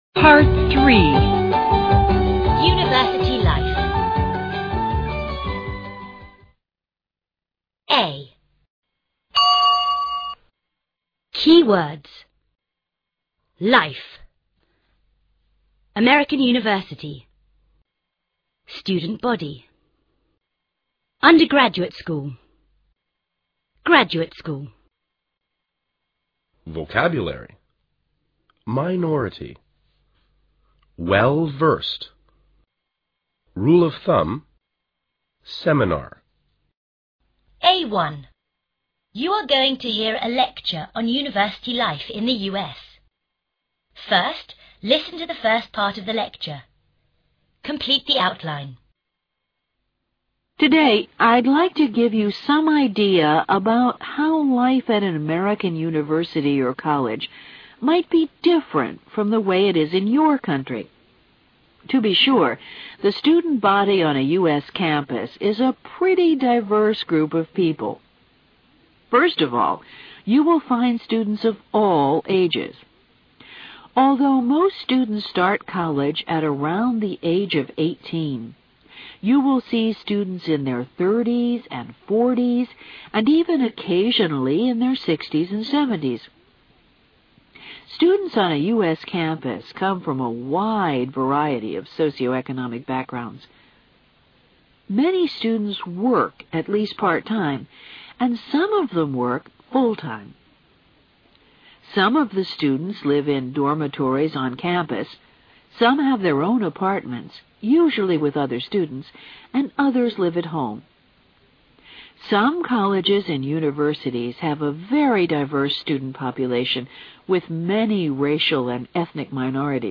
A1. you are going to hear a lecture on university life in the U.S. First, listen to the first part of the lecture.